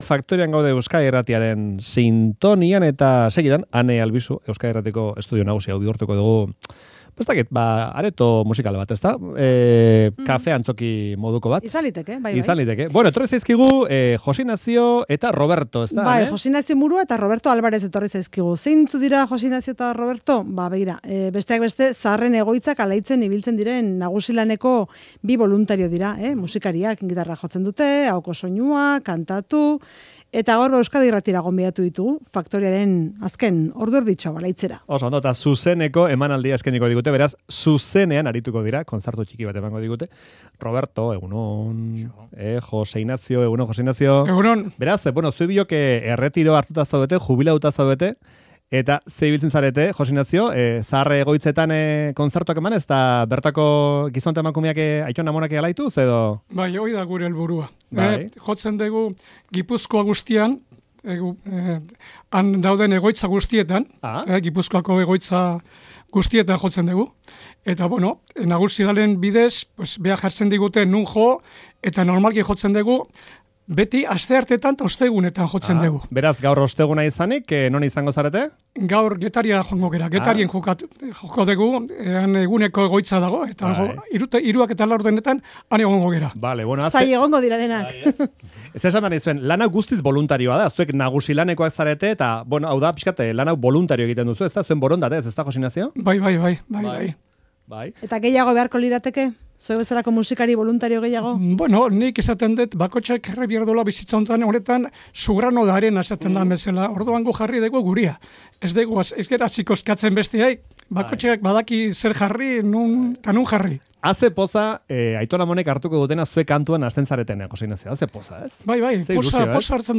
Gipuzkoako adinekoen egoitzatan kantuan aritzen dira astean bitan. Gaur Euskadi Irratiko estudioa alaitu digute.